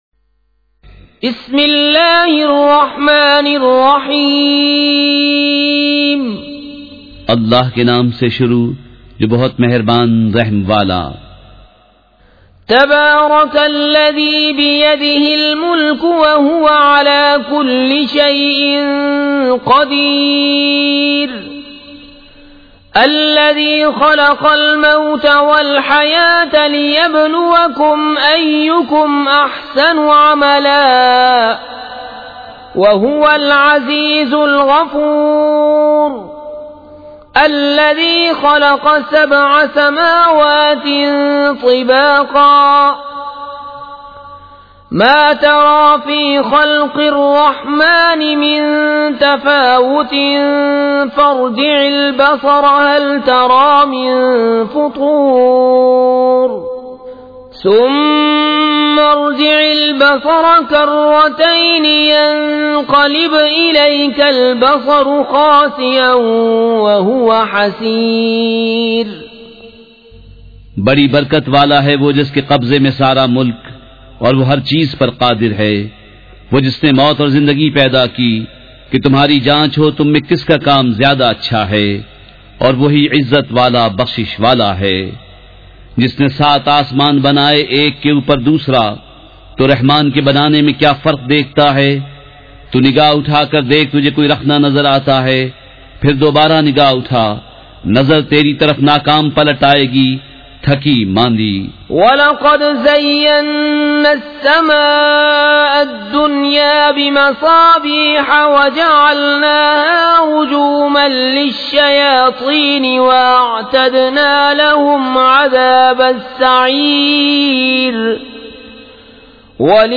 سورۃ الملک مع ترجمہ کنزالایمان ZiaeTaiba Audio میڈیا کی معلومات نام سورۃ الملک مع ترجمہ کنزالایمان موضوع تلاوت آواز دیگر زبان عربی کل نتائج 6178 قسم آڈیو ڈاؤن لوڈ MP 3 ڈاؤن لوڈ MP 4 متعلقہ تجویزوآراء